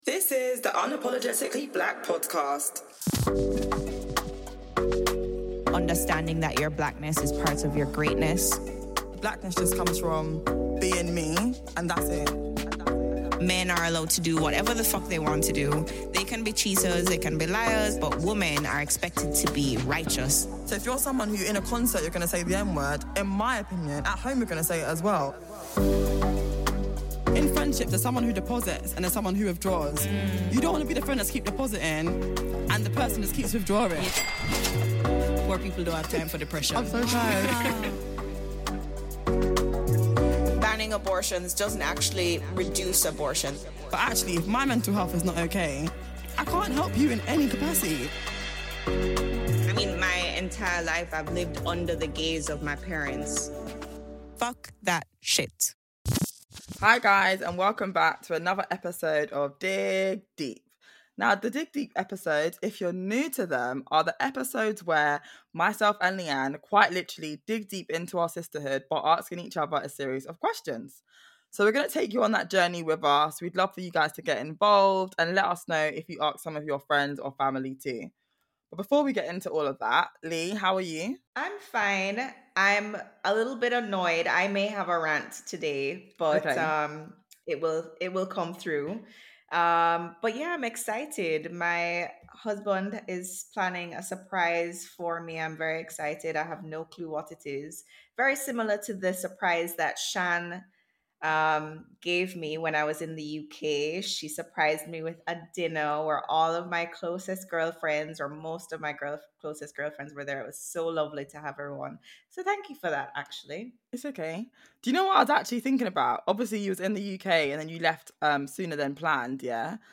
We ask each other a series of tough and often intimate questions for the first time.